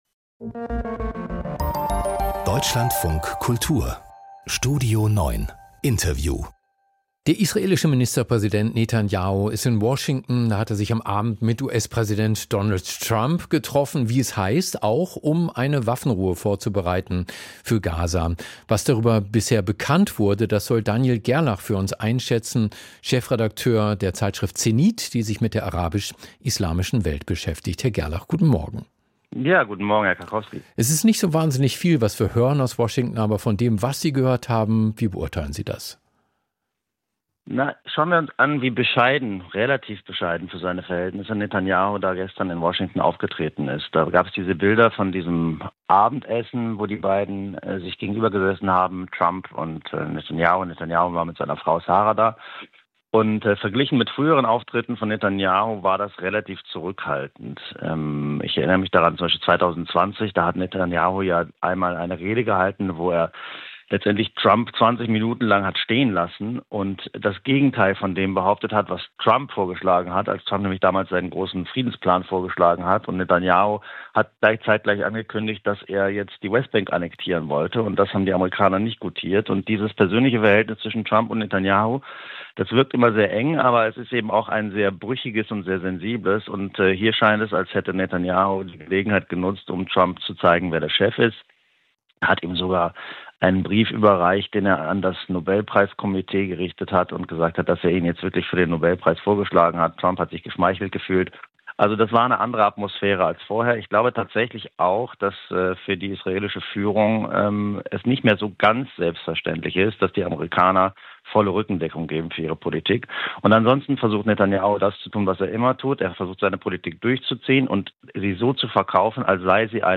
Das Interview im Deutschlandfunk Kultur greift kulturelle und politische Trends ebenso auf wie...